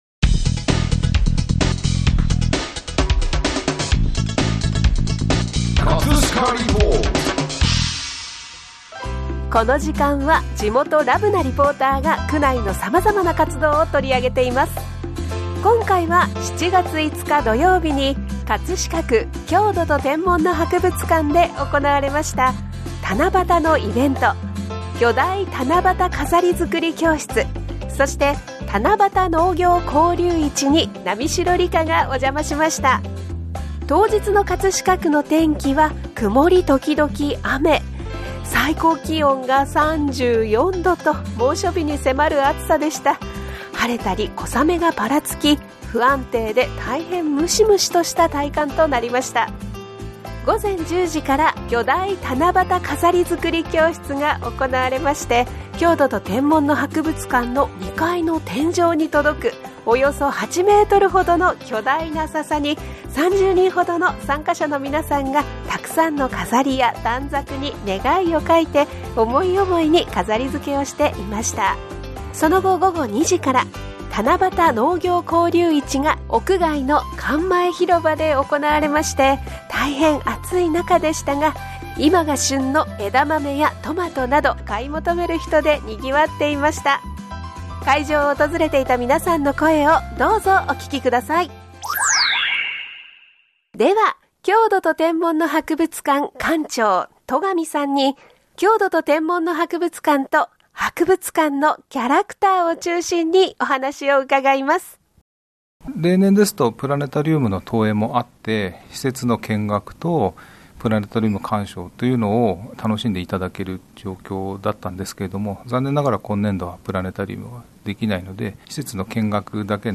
会場を訪れていた皆さんの声をどうぞお聴きください。